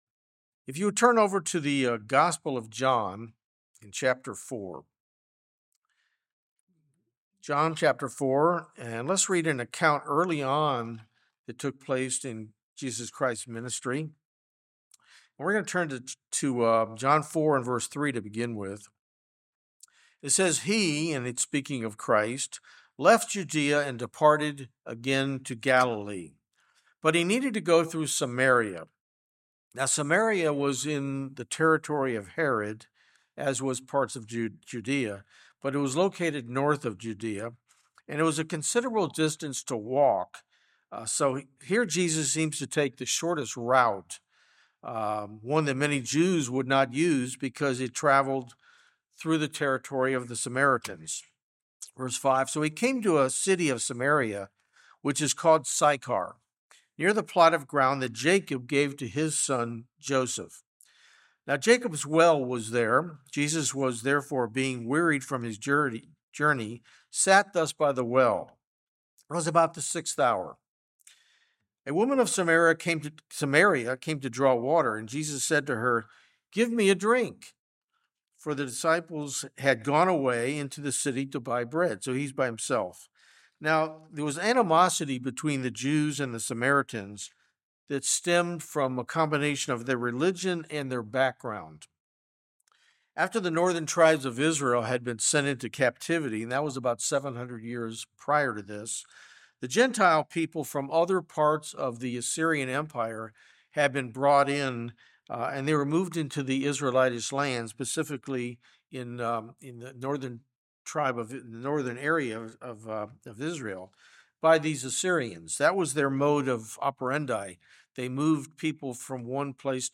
Sermons
Given in Atlanta, GA